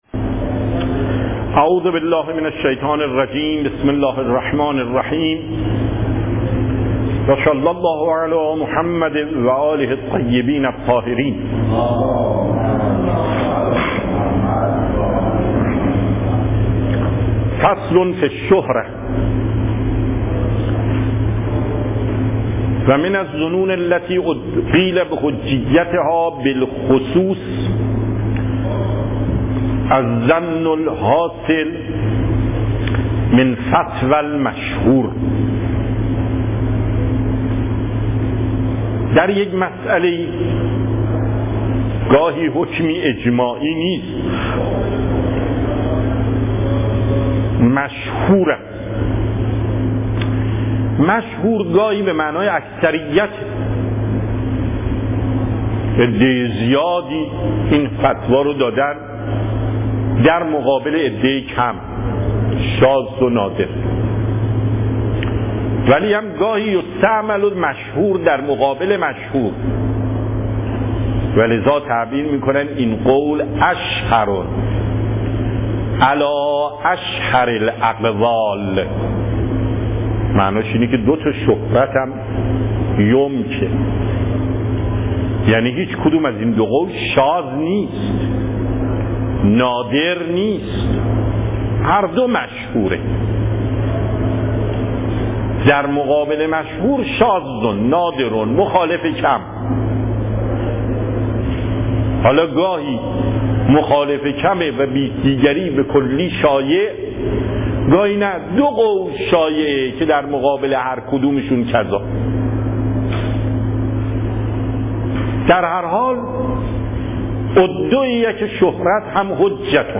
صوت و تقریر درس پخش صوت درس: متن تقریر درس: ↓↓↓ تقریری ثبت نشده است.
درس اصول آیت الله محقق داماد